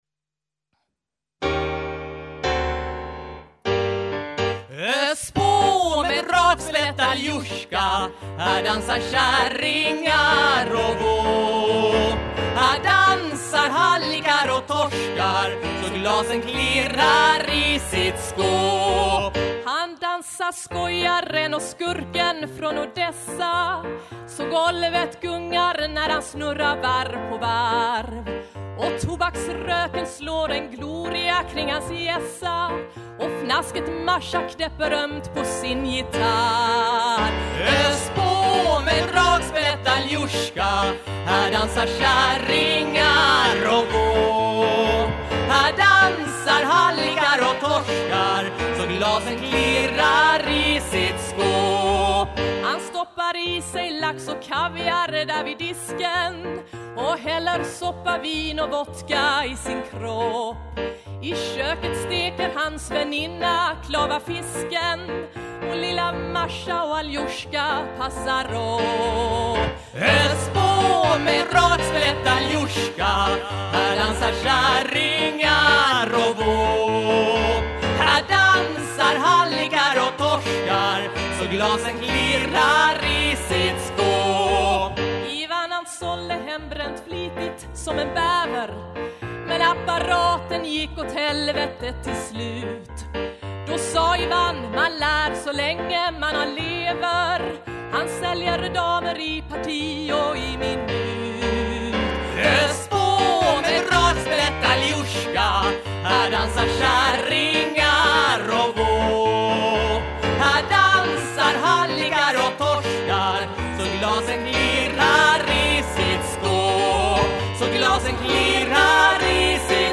2008-11-03 Musikteater: Kärlekens pris.